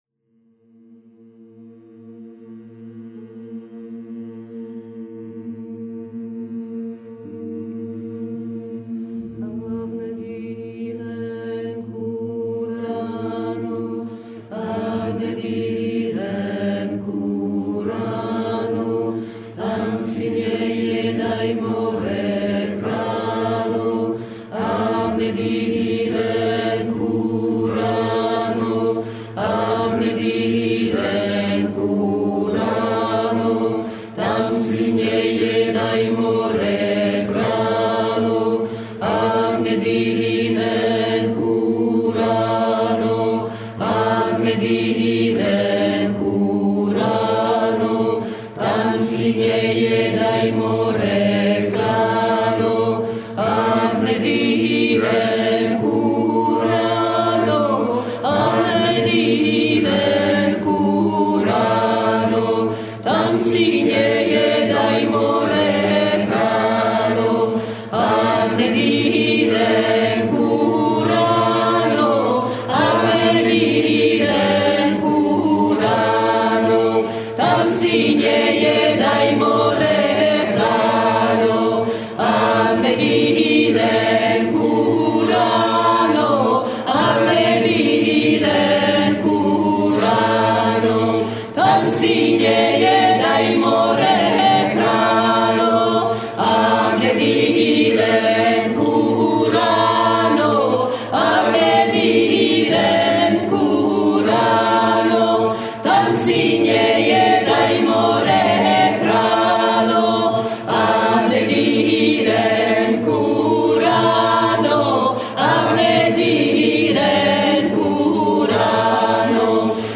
Diffusion distribution ebook et livre audio - Catalogue livres numériques
De récits en contes traditionnels, ces 11 conteurs francophones vous feront partager avec sincérité et générosité la diversité du patrimoine linguistique français…